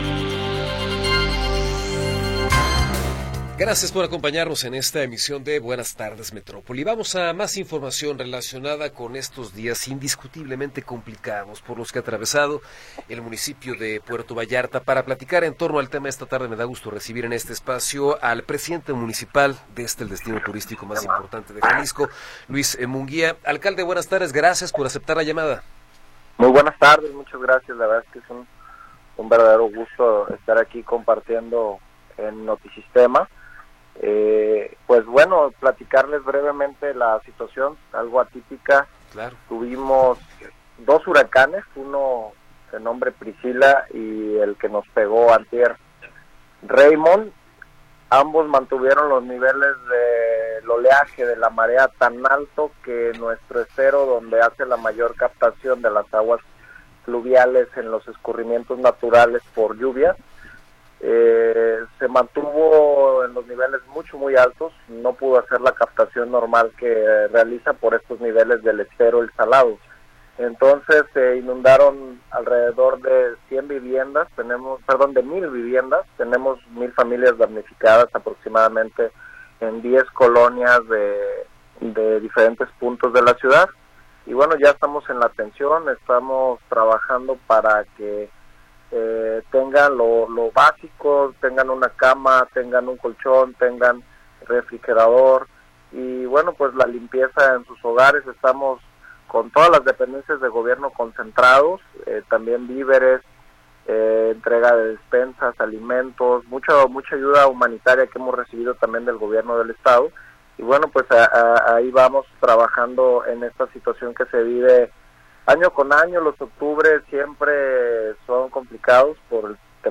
Entrevista con Luis Ernesto Munguía González
Luis Ernesto Munguía González, Presidente Municipal de Puerto Vallarta, nos habla sobre las afectaciones por las recientes lluvias en el municipio.